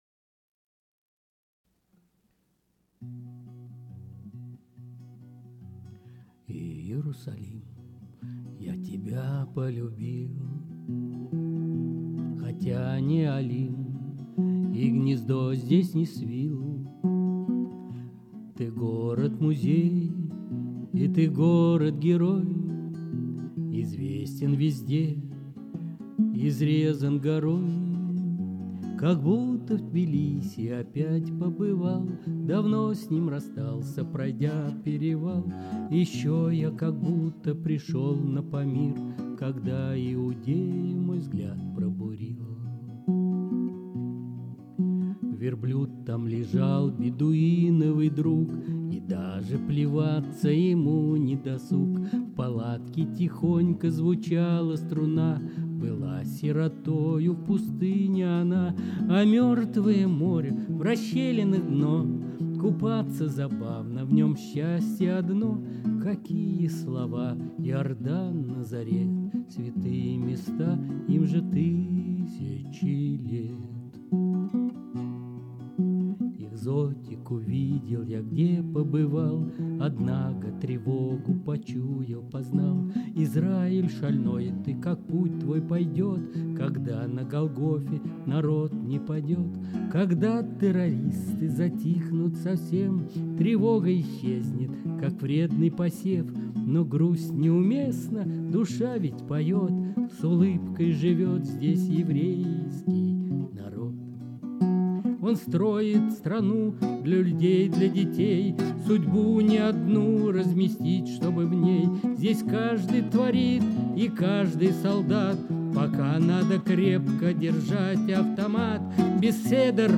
Music, vocal, guitar